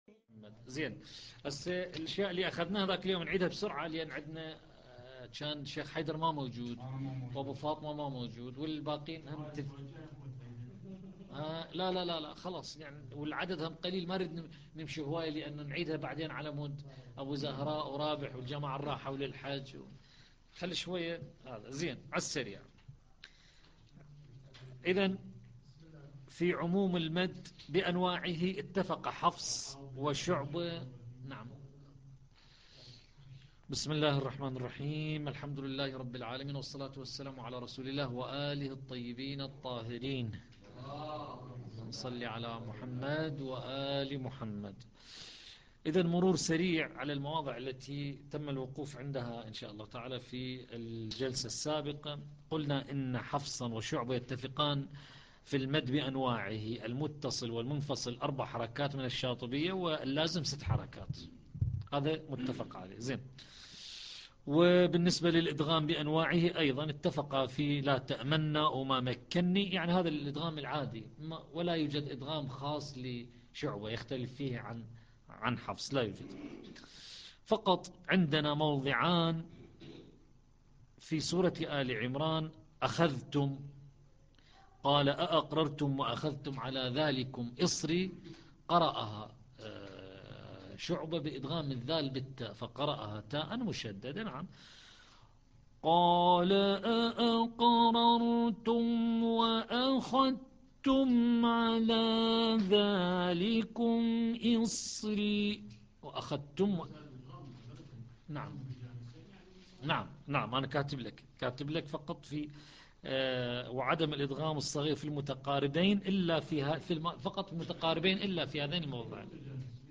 الدرس العاشر - لحفظ الملف في مجلد خاص اضغط بالزر الأيمن هنا ثم اختر (حفظ الهدف باسم - Save Target As) واختر المكان المناسب